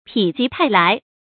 成语繁体 否極泰來 成语简拼 pjtl 成语注音 ㄆㄧˇ ㄐㄧˊ ㄊㄞˋ ㄌㄞˊ 常用程度 常用成语 感情色彩 中性成语 成语用法 紧缩式；作谓语、宾语；含褒义 成语结构 连动式成语 产生年代 古代成语 成语正音 否，不能读作“fǒu”。